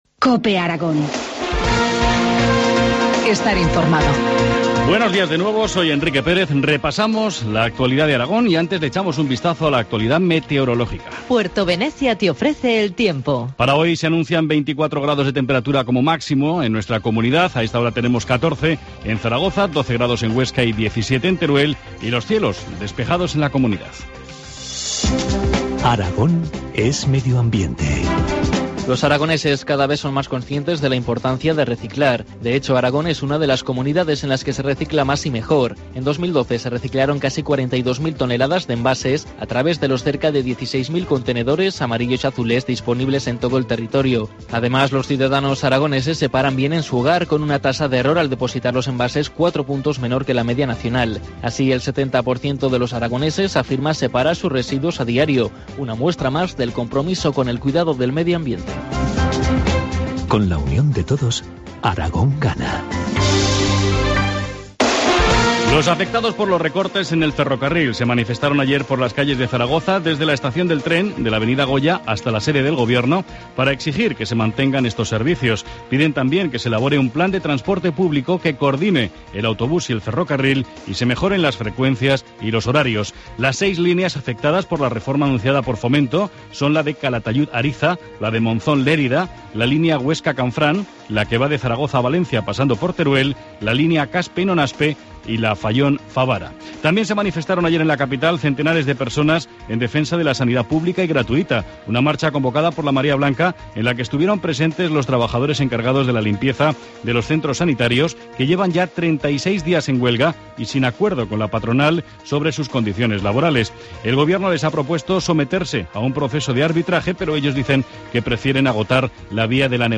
Informativo matinal, lunes 24 de junio, 8.25 horas